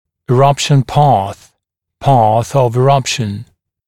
[ɪ’rʌpʃn pɑːθ] [pɑːθ əv ɪ’rʌpʃn] [и’рапшн па:с] [па:с ов и’рапшн] траектория прорезывания